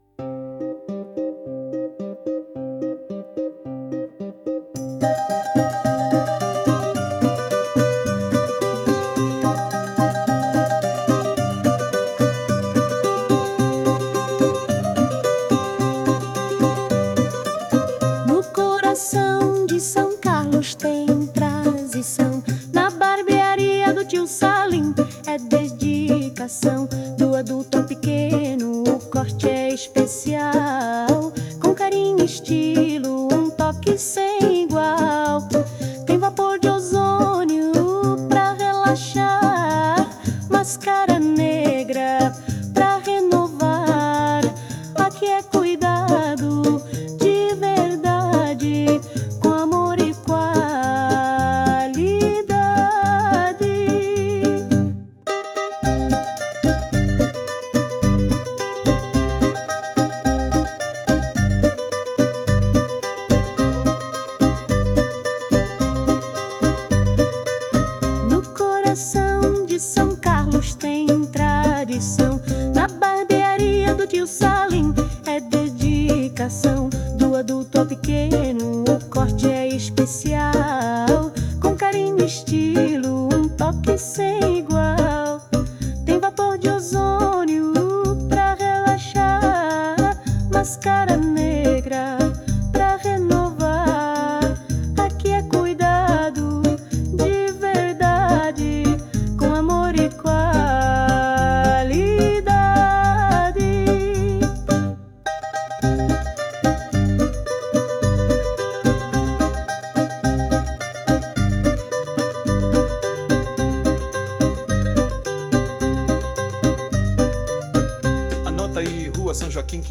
JINGLE COMERCIAL
Árabe/Libanês Tradição Barbearia